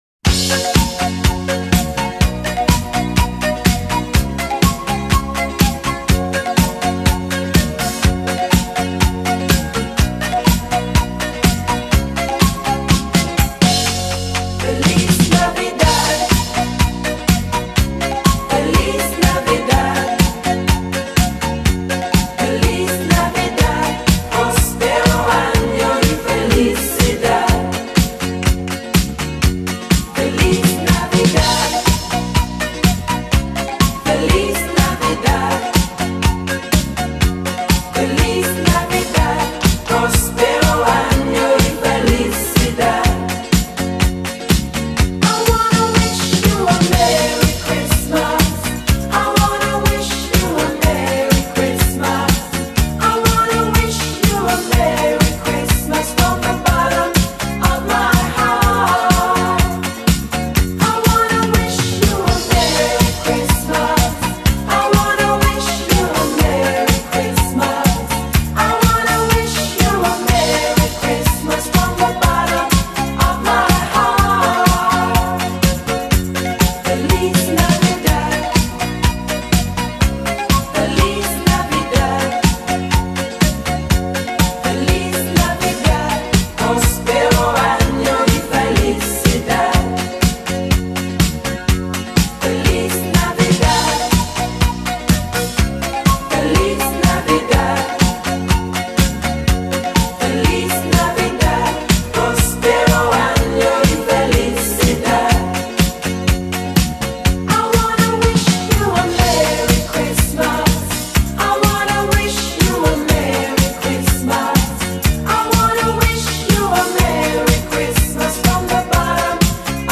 Christmas Song